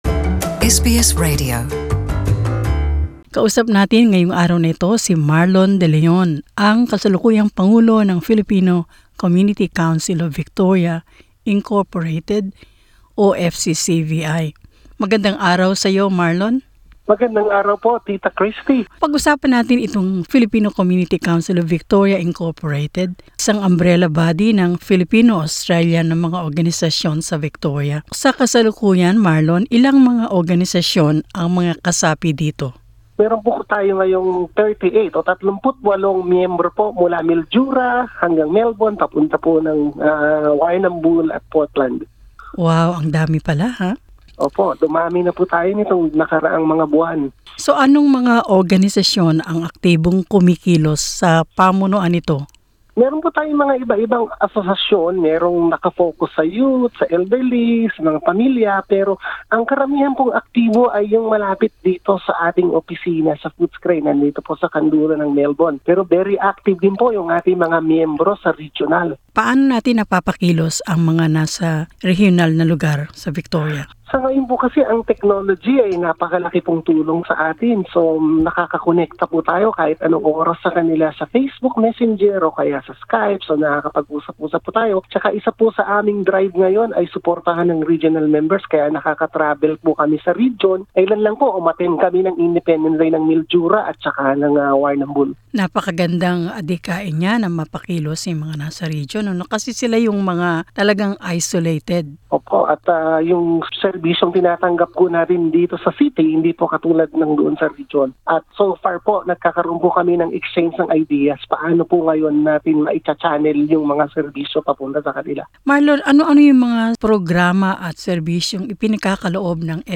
Layunin ng Filipino Community Council of Victoria, Inc. o FCCVI, na itinatag noong taong 1988, na makapagbuo ng matatag na komunidad Pilipino-Australyano. Sa isang panayam